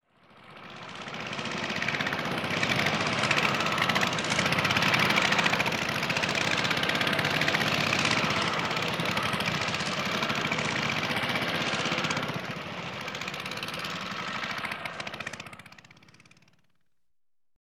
Звуки мопеда, скутера
Звук мотора старенького мопеда